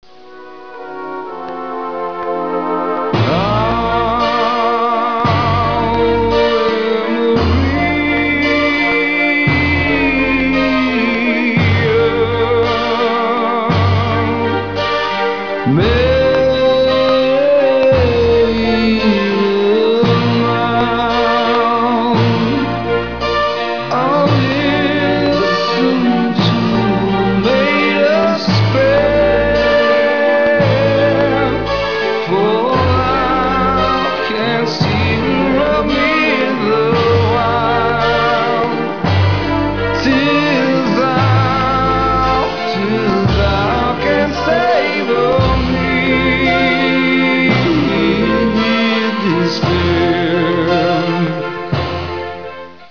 [AU: 52s, 8-bit, 11kHz, mono, 0.5MB]